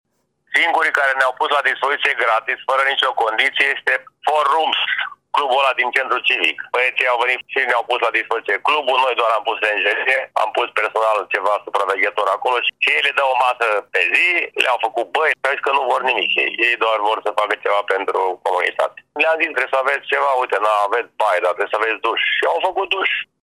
Primarul George Scripcaru a declarat că sunt singurii patroni din oraș care nu au cerut nimic în schimb pentru acest efort: